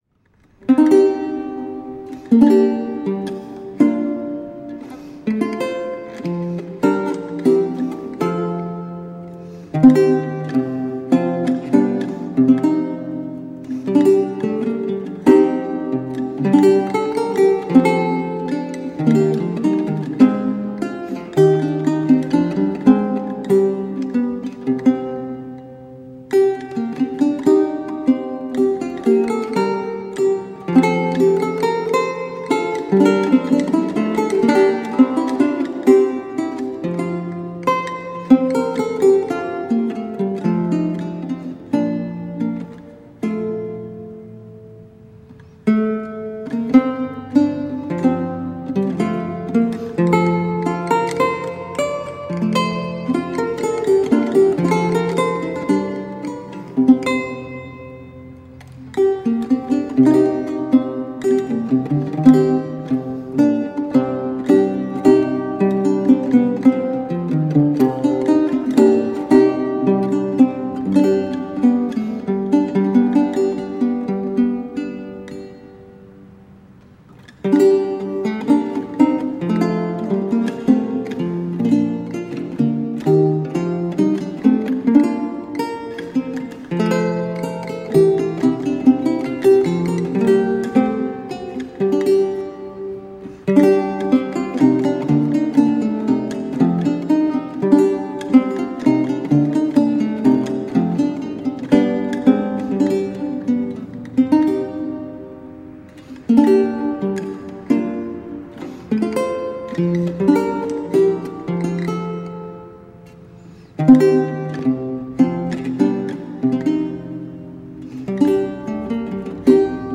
Vihuela, renaissance and baroque lute.
Classical, Renaissance, Instrumental, Lute